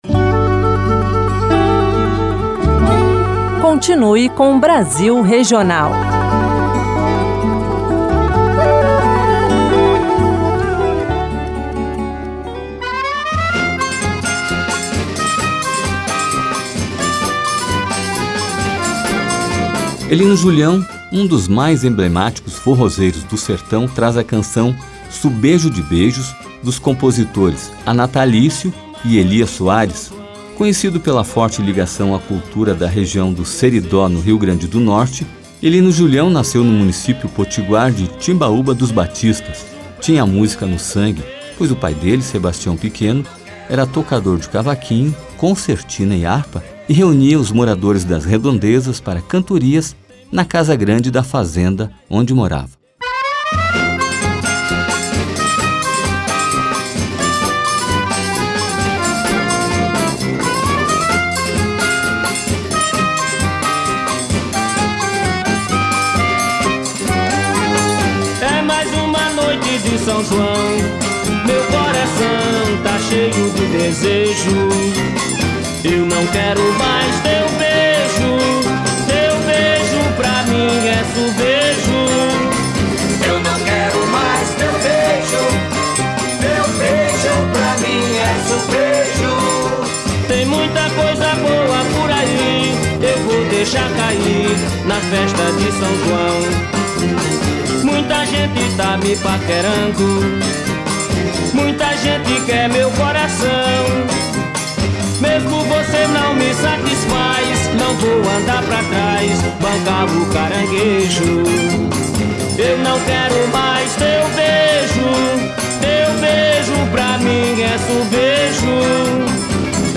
que fez uma mistura interessante colocando a rabeca
para torcar ritmos da América Latina como cumbia e merengue.